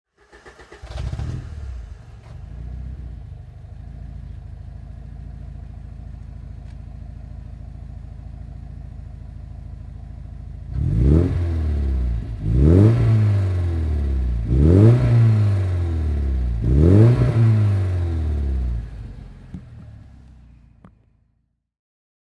Alfa Romeo 75 Twin Spark "Limited Edition" (1992) - Starten und Leerlauf